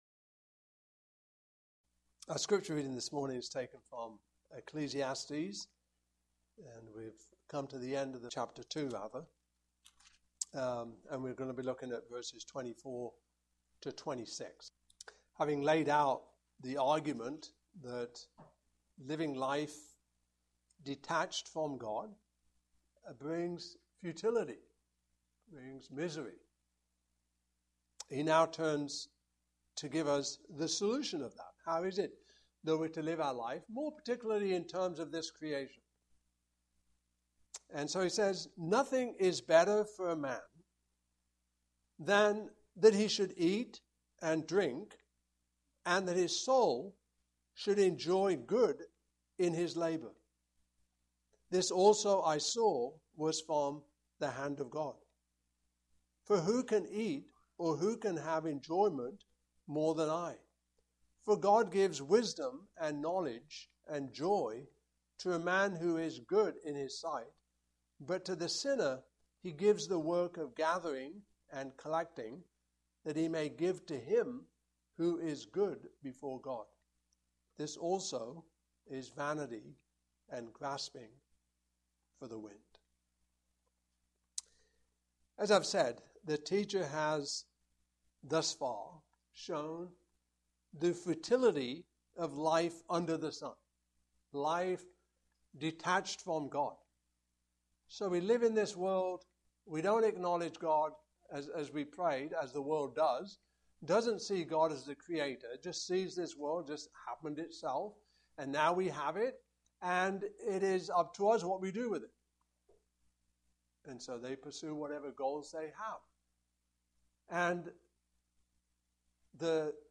Series: The Book of Ecclesiastes Passage: Ecclesiastes 2:24-26 Service Type: Morning Service